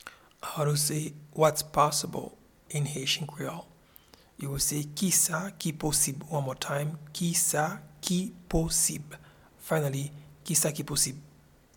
Pronunciation and Transcript:
Whats-possible-in-Haitian-Creole-Kisa-ki-posib.mp3